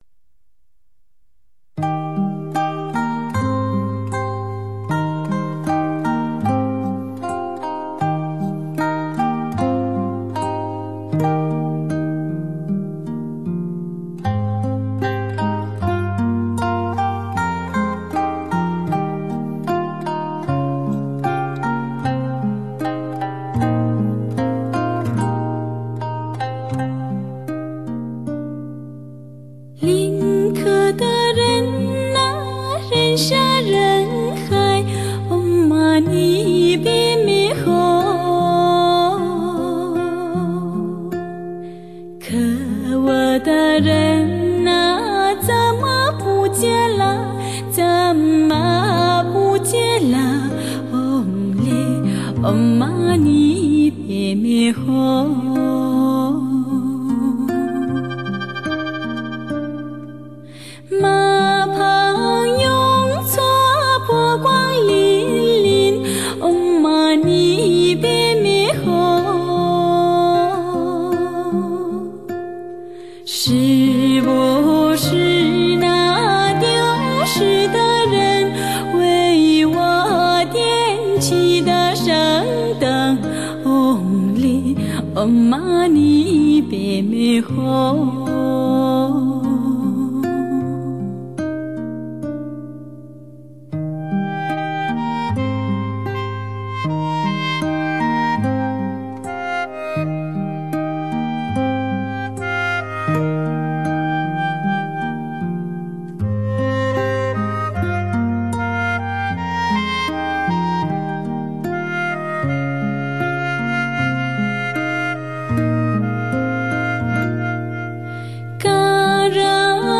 发烧精品-发烧音乐系列
全情唱响新疆、西藏、内蒙民歌，释放出各种不同的声色香气。
配器：笙、柳琴、尼龙吉他